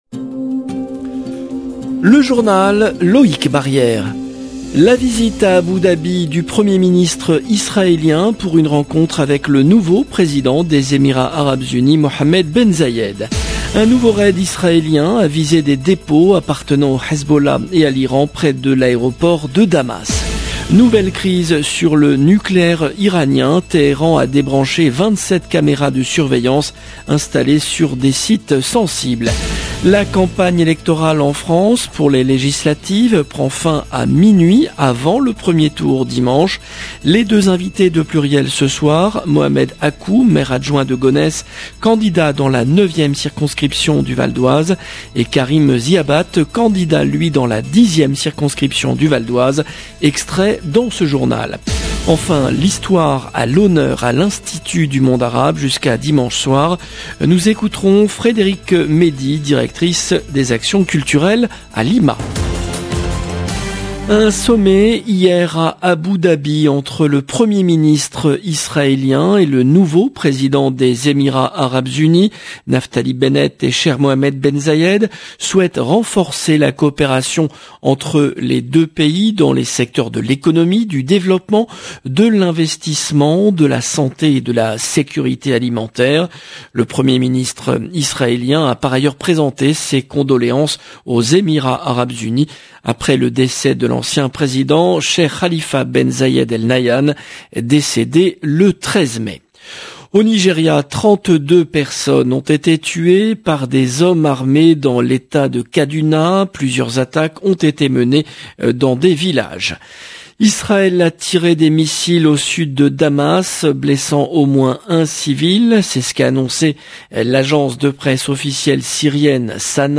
LB JOURNAL EN LANGUE FRANÇAISE